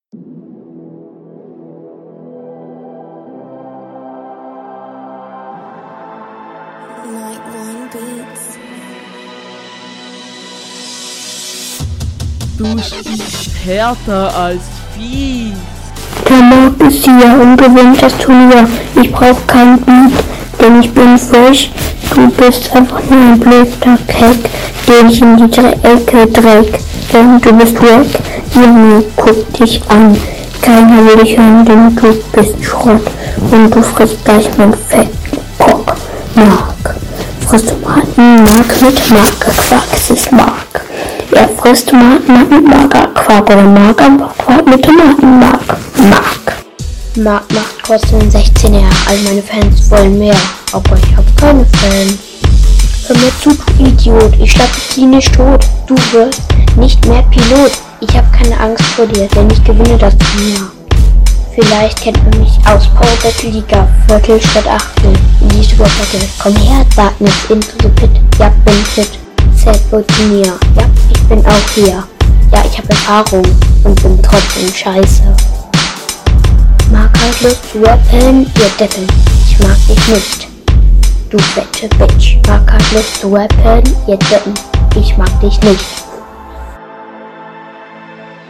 Flow: beat ignoriert Text: egal Soundqualität: übersteuert Allgemeines: Beat ignoriert, deshalb kein punkt